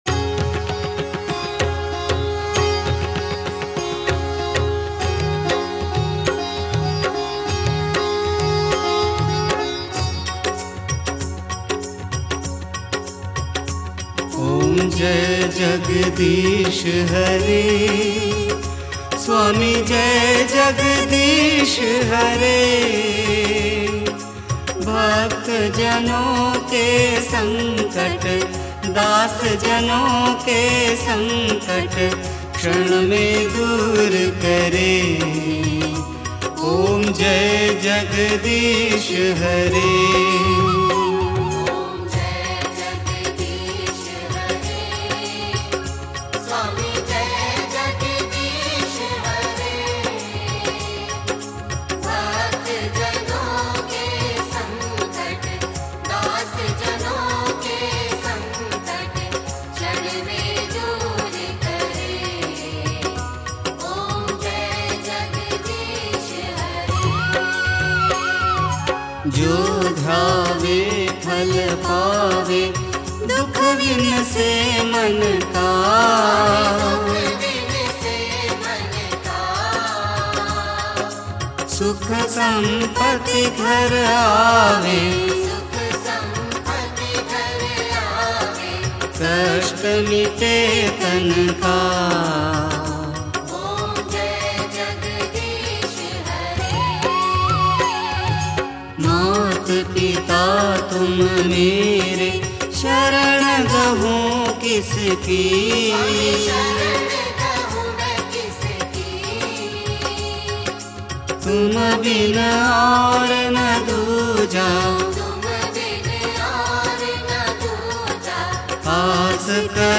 Aartiyan